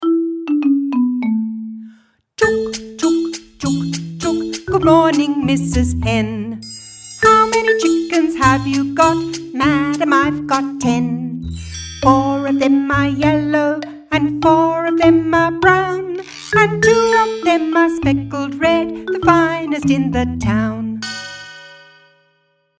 Nursery rhyme Chook Chook
Here is a recording of me singing the little tune I wrote because I couldn’t find one already current for this cute nursery rhyme.